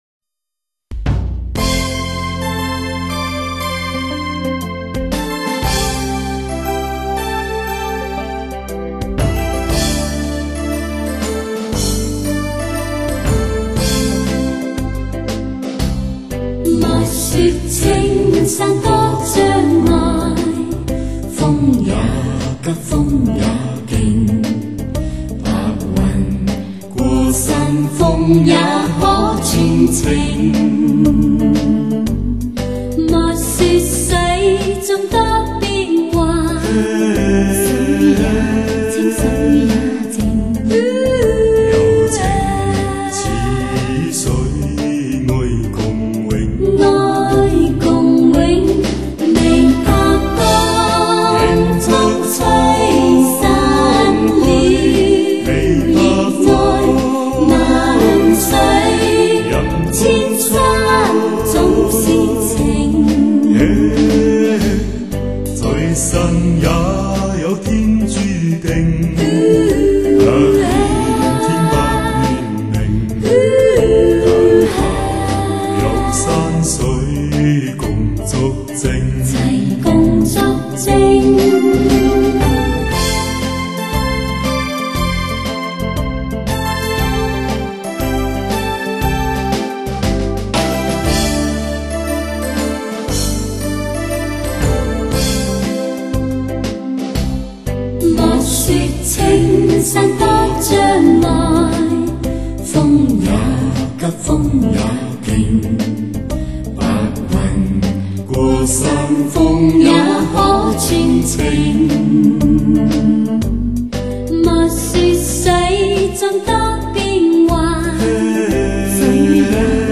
细腻的音乐，动人的旋律，感人的歌词，使您全情投入音乐的氛围之中。